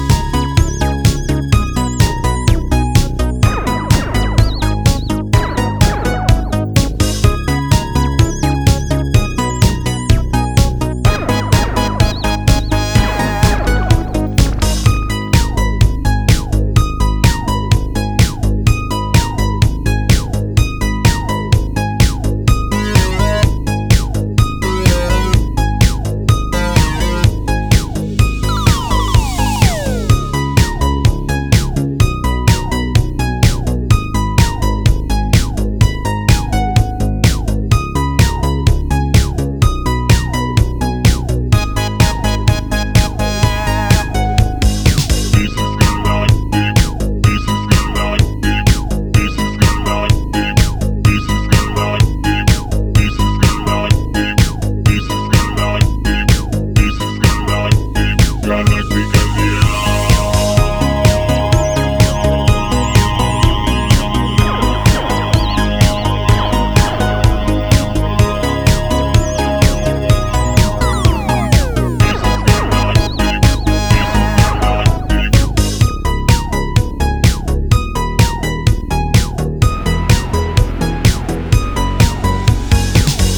classic ITALODISCO bomb of the time